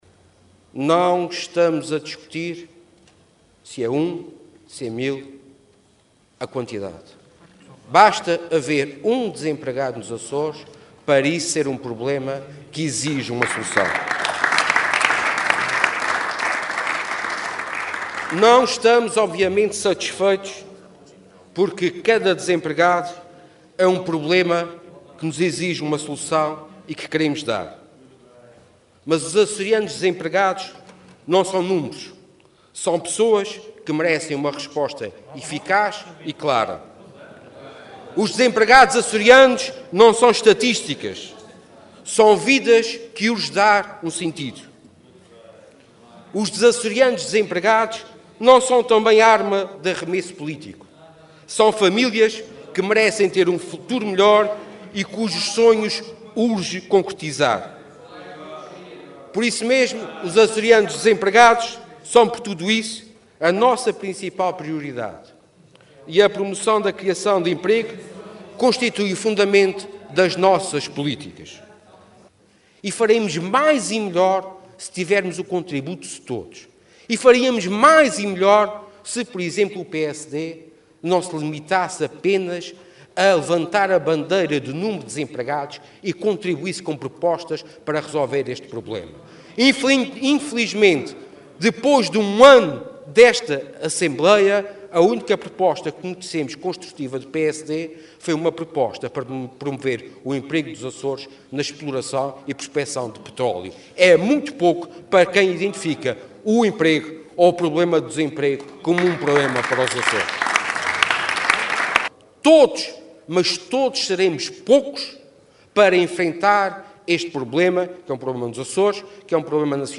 Sérgio Ávila, que falava na Assembleia Legislativa durante o debate suscitado por uma declaração política sobre o desemprego, reiterou que o Governo dos Açores tem nos desempregados a sua maior preocupação e na promoção do emprego a sua maior prioridade.